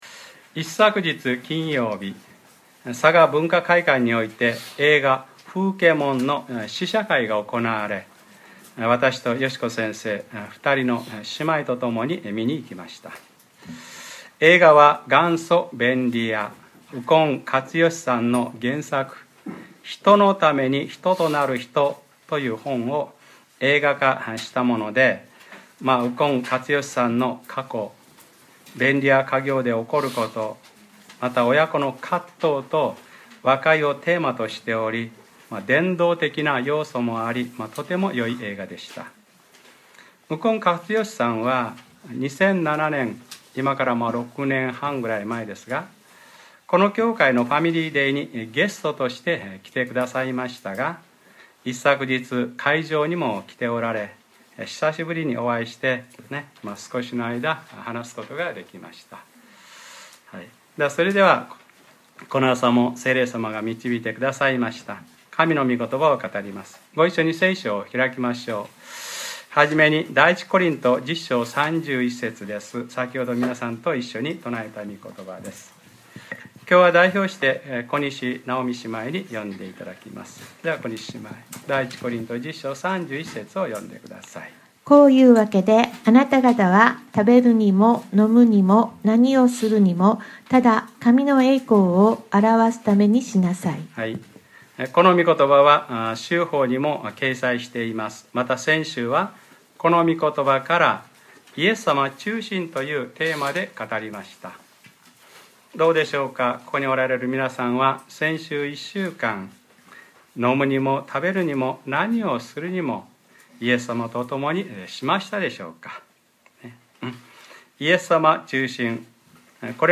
2014年 2月 9日（日）礼拝説教『9人はどこにいるのか』